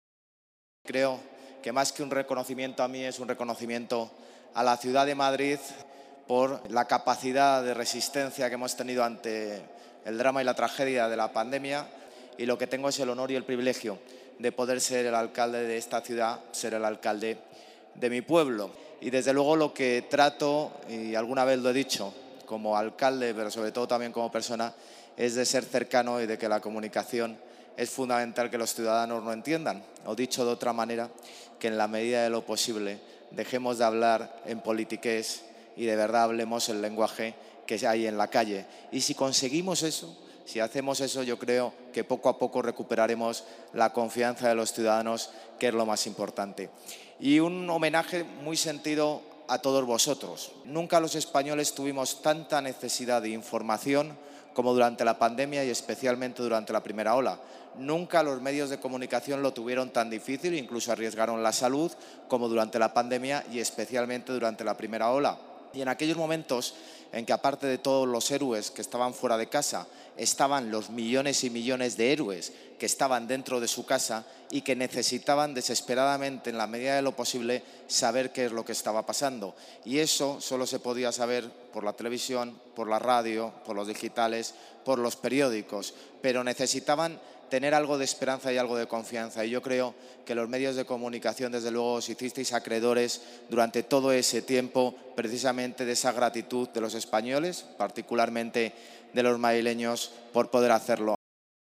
El alcalde de Madrid, José Luis Martínez-Almeida, ha recibido la Antena de Oro Política 2020, galardón de la Federación de Asociaciones de Radio y Televisión de España cuya gala se celebró anoche en la Galería de Cristal del Palacio de Cibeles.